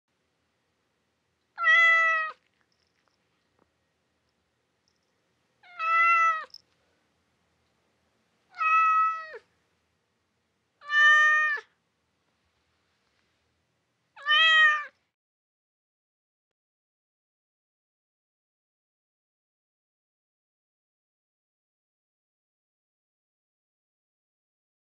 جلوه های صوتی
دانلود آهنگ میو میو گربه 2 از افکت صوتی انسان و موجودات زنده
دانلود صدای میو میو گربه 2 از ساعد نیوز با لینک مستقیم و کیفیت بالا